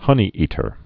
(hŭnē-ētər)